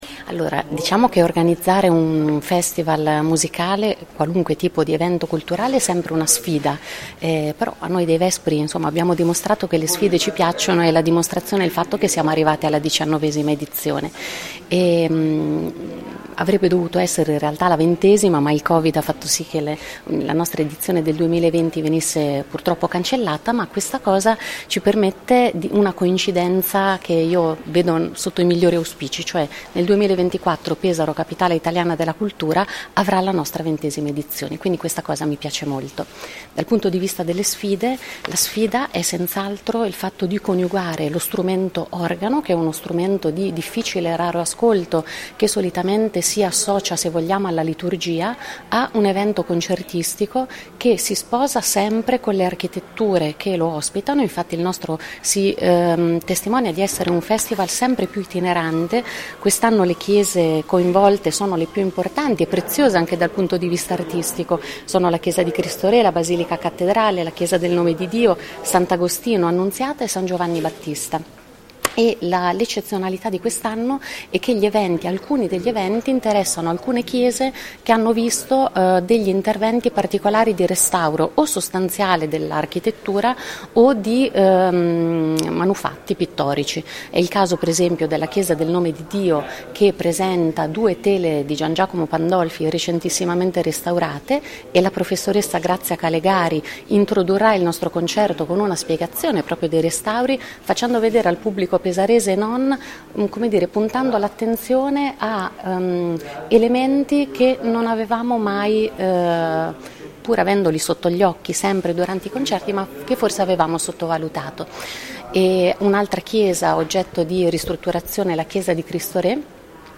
Direttore Artistico e Andrea Biancani, Consigliere della Regione Marche.